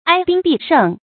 注音：ㄞ ㄅㄧㄥ ㄅㄧˋ ㄕㄥˋ
讀音讀法：